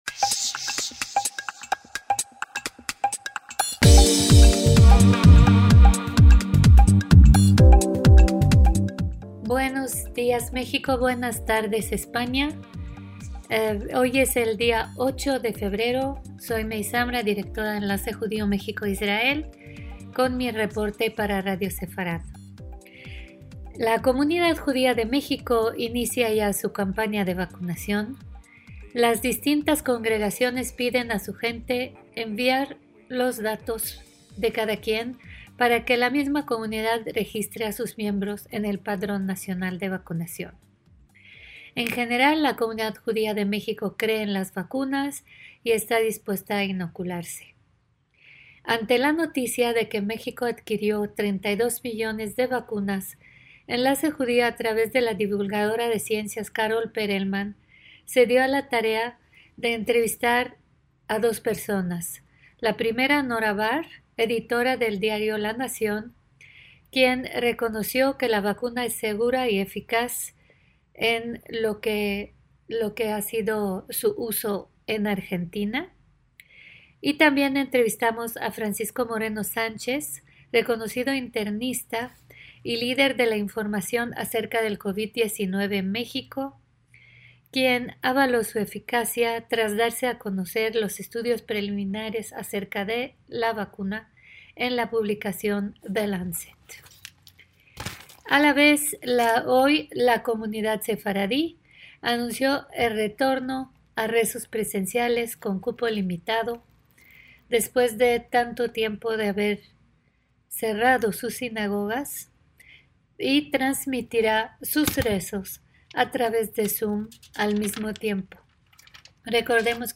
Eso y más en nuestro programa de hoy